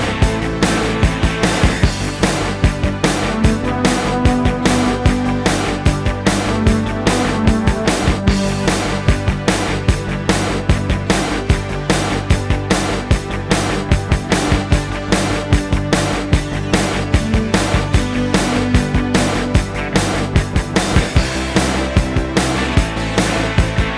Four Semitones Down Rock 3:29 Buy £1.50